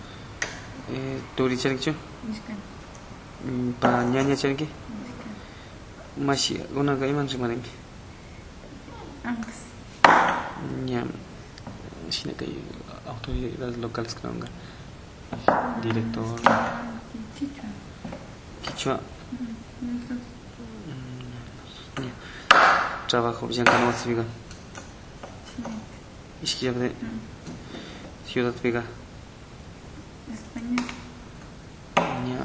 Entrevistas - Santa Cruz